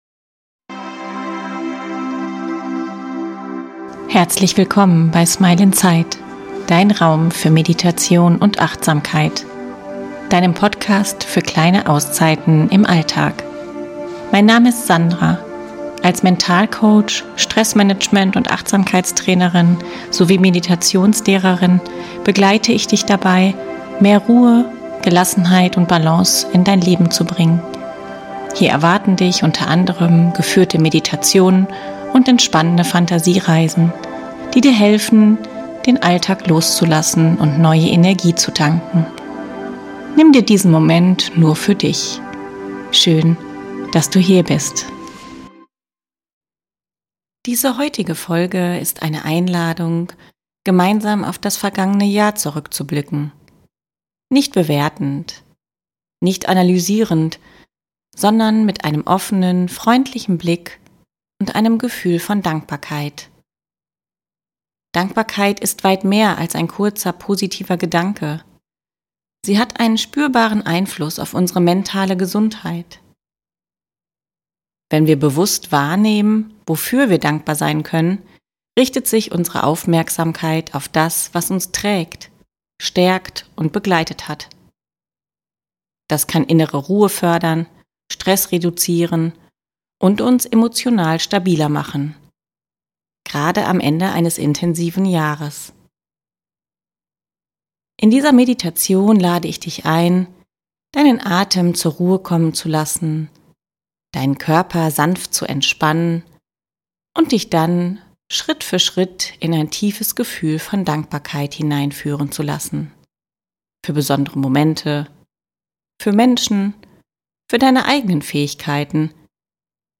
In dieser Folge erwartet dich eine geführte Dankbarkeitsmeditation zum Jahresende.